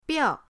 biao4.mp3